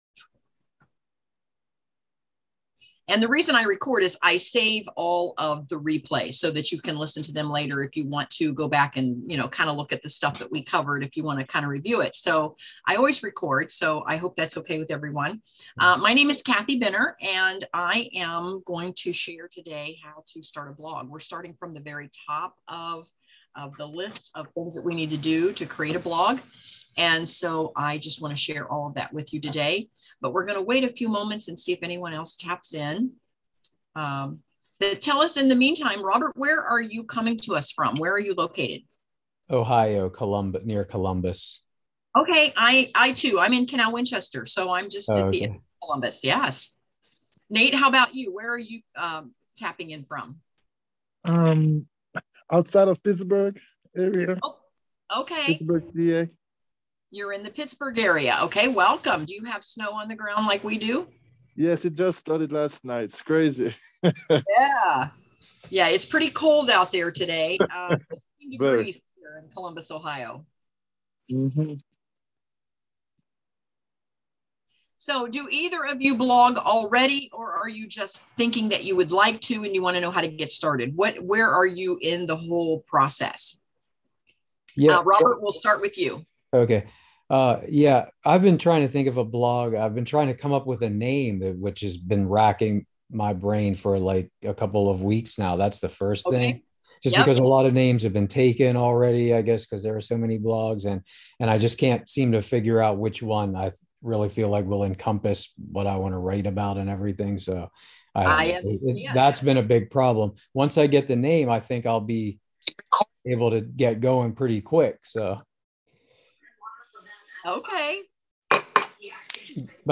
Join us for a discussion on how to choose a name for your blog. (My internet bumped me out at the end -- but lots of great discussion)